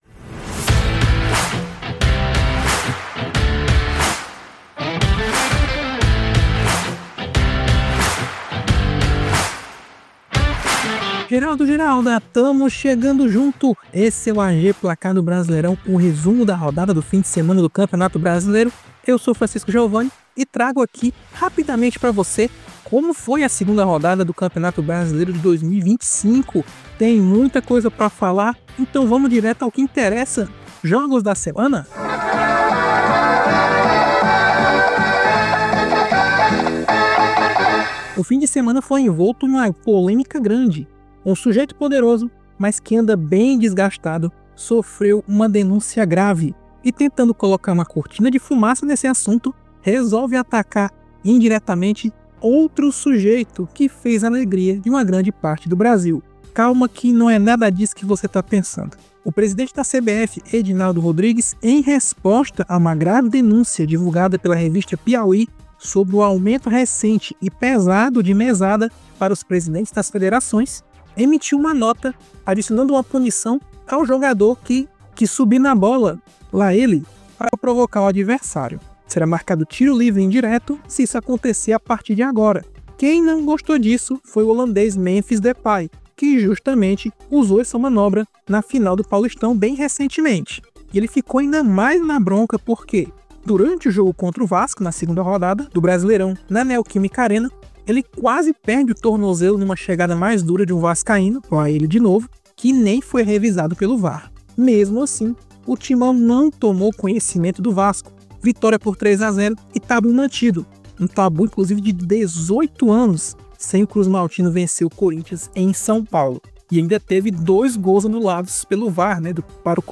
No Momento Voz da Rodada, a lenda Galvão Bueno narra o segundo gol do Corinthians contra o Vasco pela Amazon Prime!